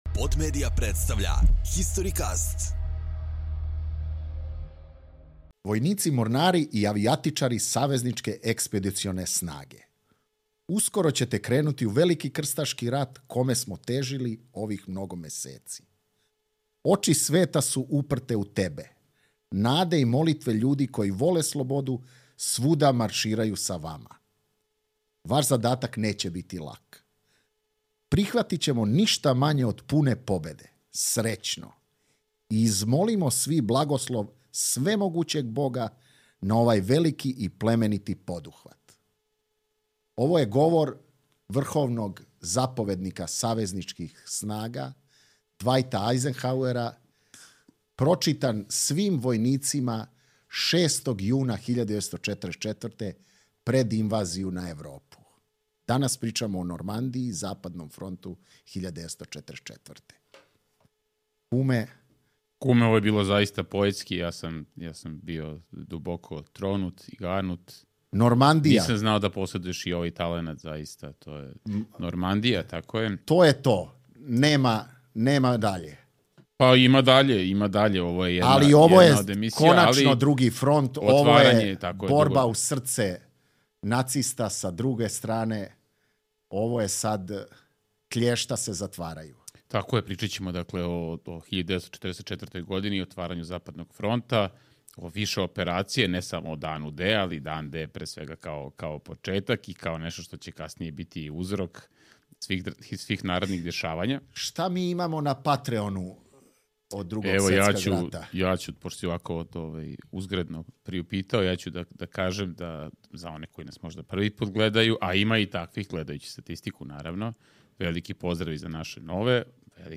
U ovoj epizodi istražujemo sudbinu nekih od najpoznatijih zdanja Beograda koja su nestala ili su u ruševinama - Hotel Jugoslavija, Pošta Beograd 2 i Generalštab. Razgovaramo i o Sajmu, još jednom simbolu grada kojem preti nestajanje.